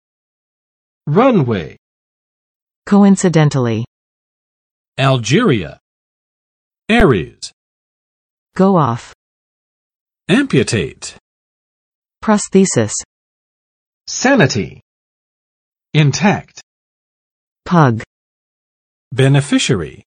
[ˋrʌn͵we] n.（机场的）跑道